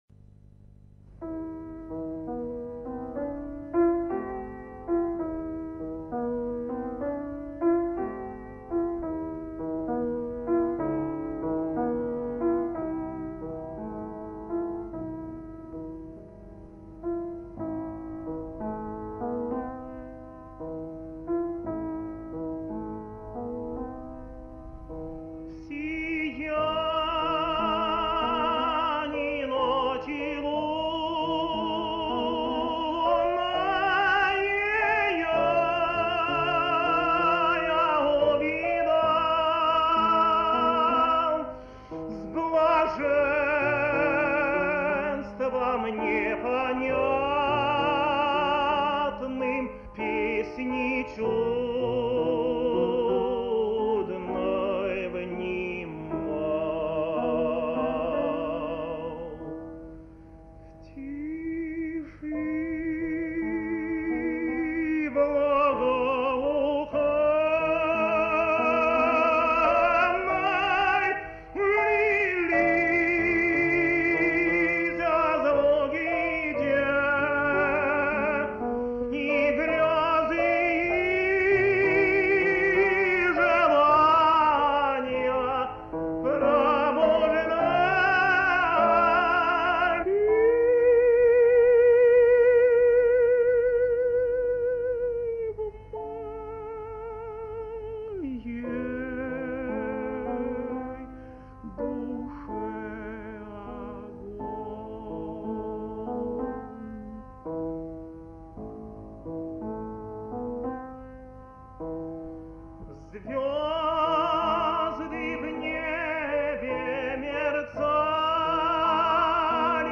Романс Надира. Исполняет И. С. Козловский.